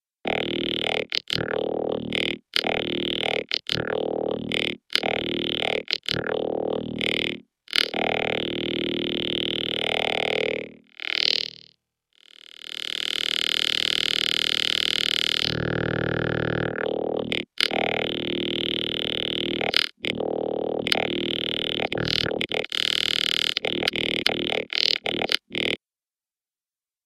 When you play a note you should hear what is essentially a sample of a voice saying ‘electronic’.
Try lowering the motion control on this oscillator and you should hear the voice slow down. When you reach zero the voice will remain stuttering on one tiny segment or ‘grain’ of the sound, much like our combinator patch.